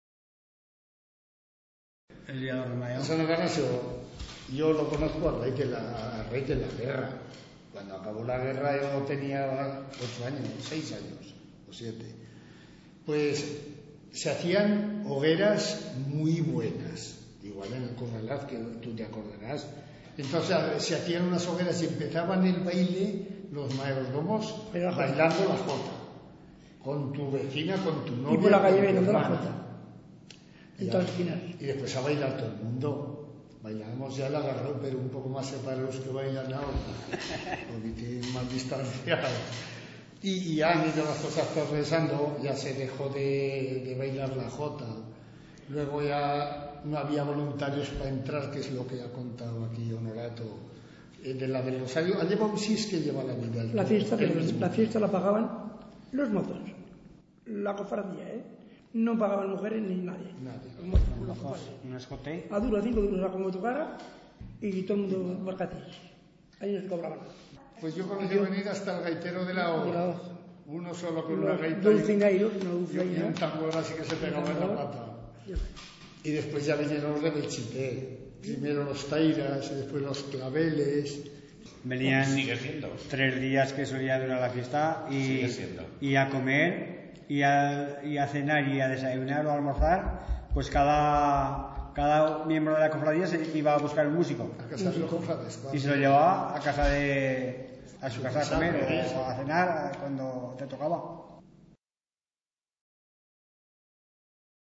CONVERSACIONES
18. Informantes: Grupo de informantes